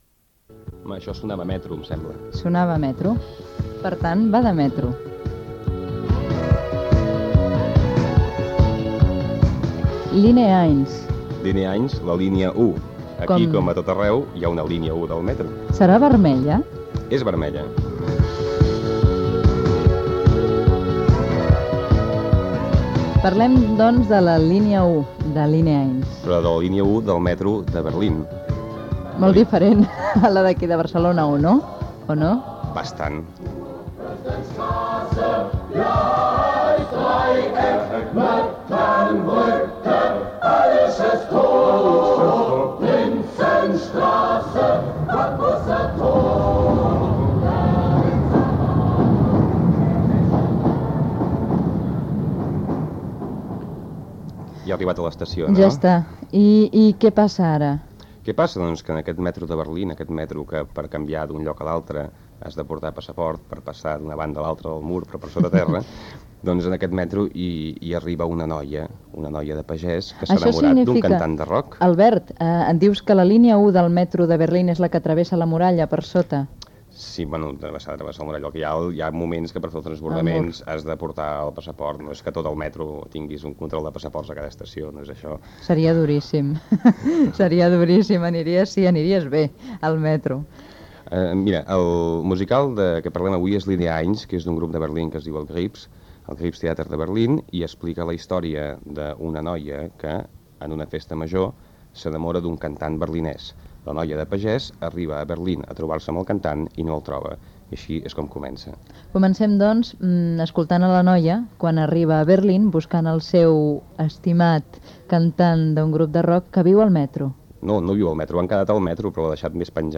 Entreteniment
Presentador/a